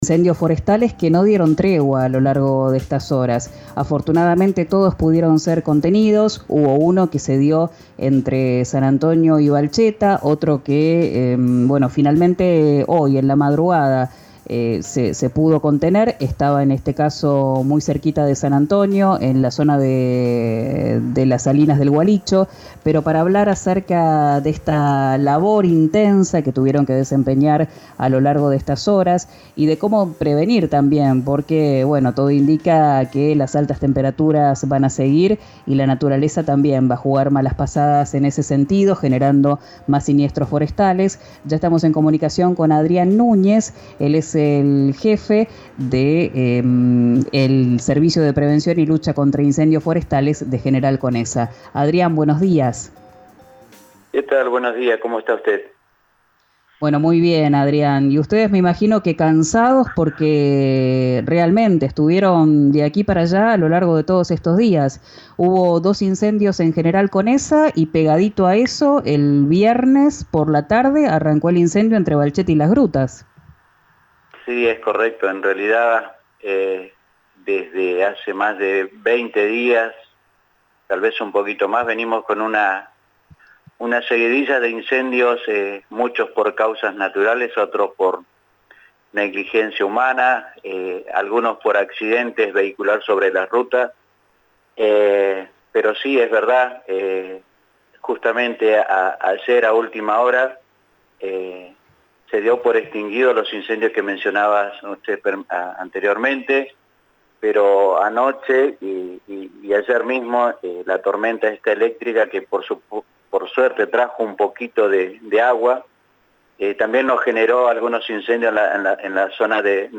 Adrián Nuñez, jefe del SPLIF, habló con RÍO NEGRO RADIO.